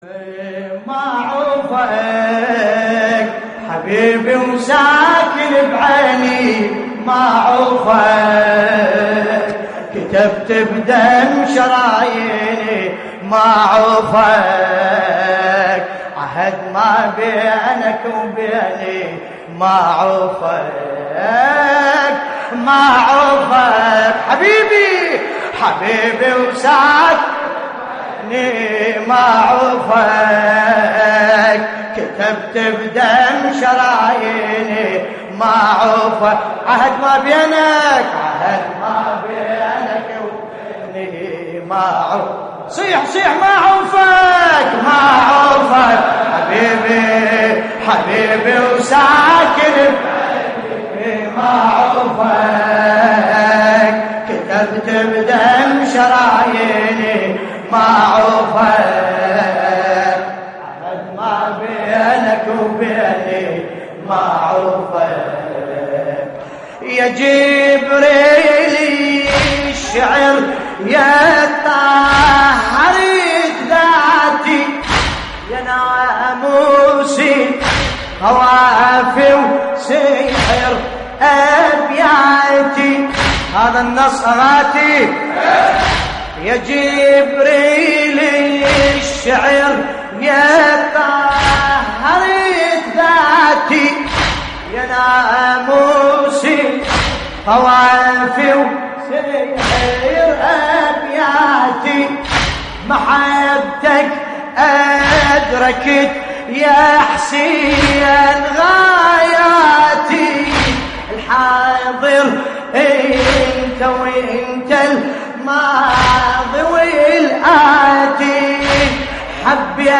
تحميل : ما عوفك حبيبي وساكن بعيني ما عوفك / الرادود باسم الكربلائي / اللطميات الحسينية / موقع يا حسين